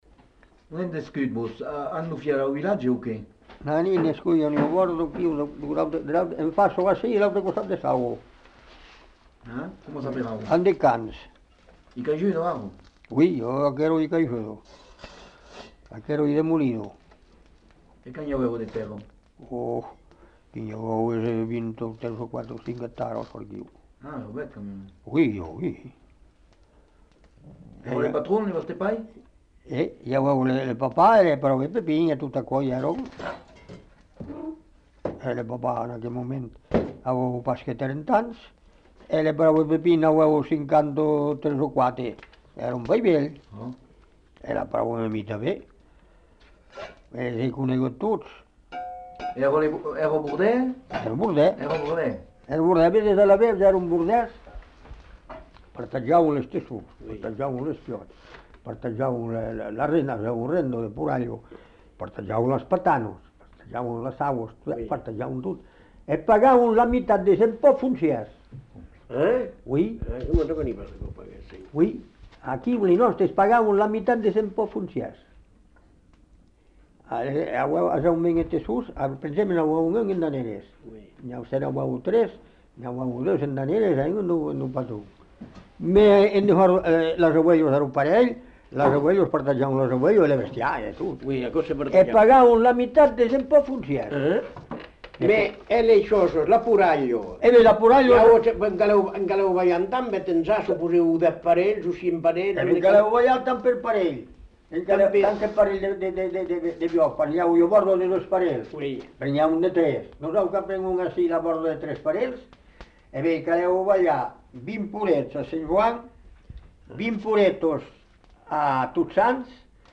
Lieu : Cazaux-Savès
Genre : témoignage thématique